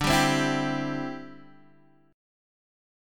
D9sus4 chord